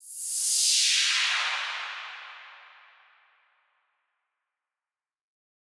Index of /musicradar/shimmer-and-sparkle-samples/Filtered Noise Hits
SaS_NoiseFilterB-04.wav